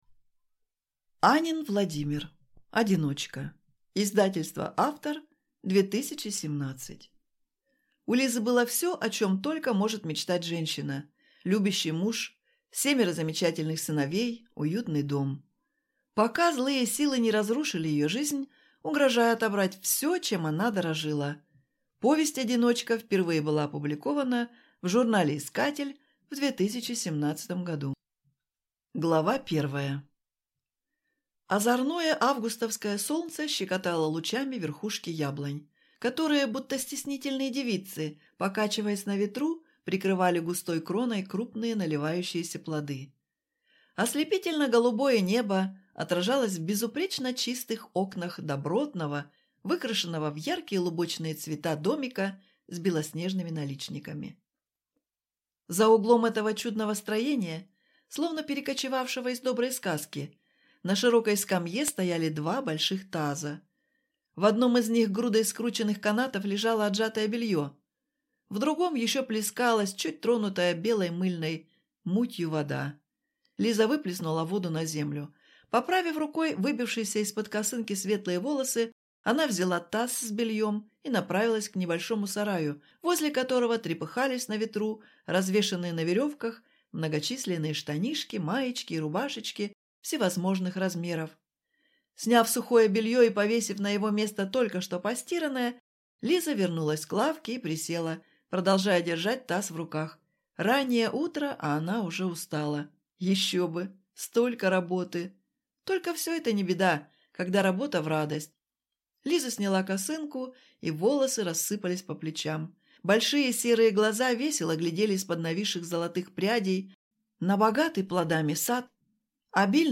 Аудиокнига Одиночка | Библиотека аудиокниг